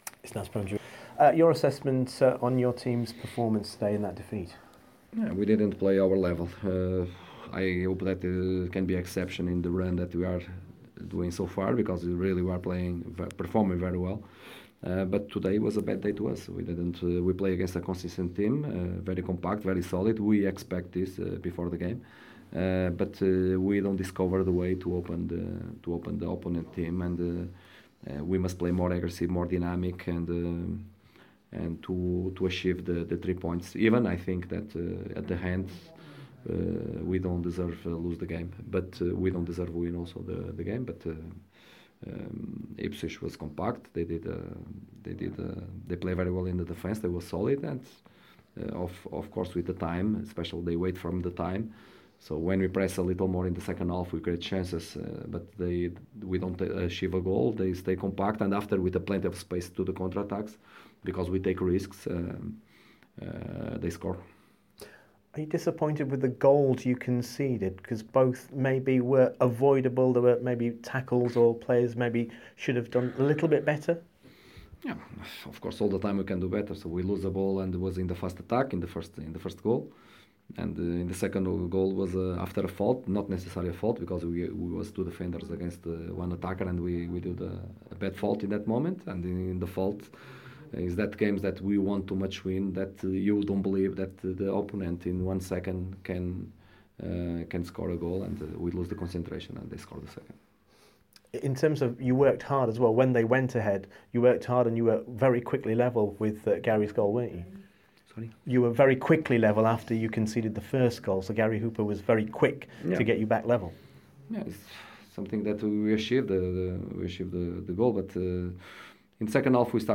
Listen as Sheffield Wednesday boss Carlos Carvalhal talks about his side's 2-1 defeat at home to Ipswich Town